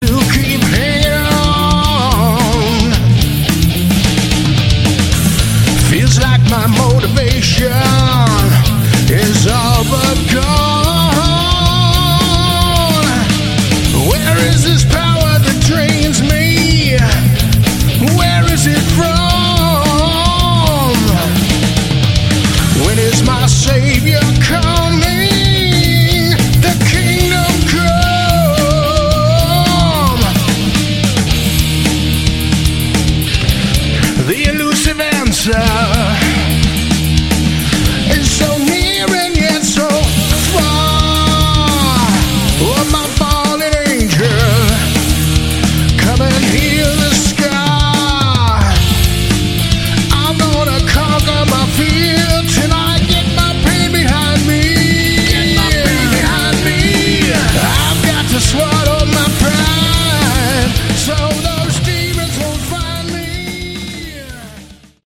Category: Hard Rock
guitars
vocals